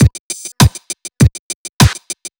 FK100BEAT1-L.wav